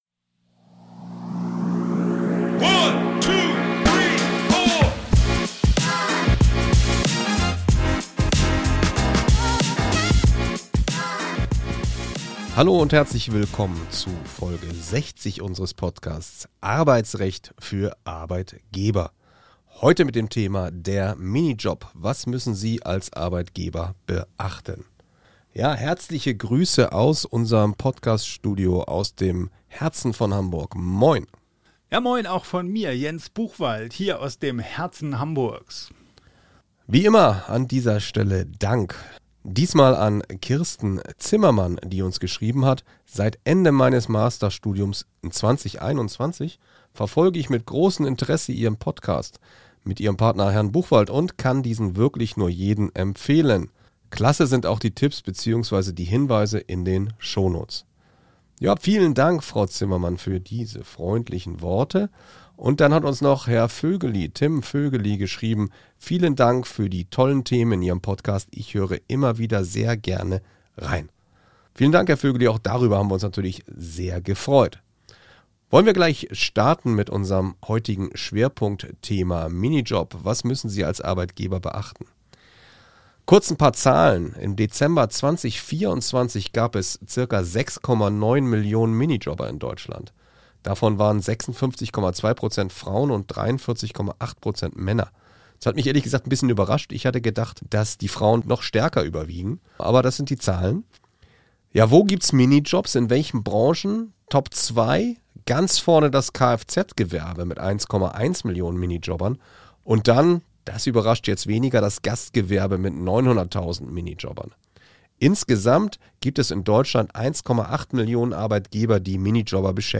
Für Arbeitgeber - neue Urteile, neue Gesetze, Praxistipps und grundlegende Informationen rund um alle arbeitsrechtlichen Fragestellungen. Zwei Fachanwälte für Arbeitsrecht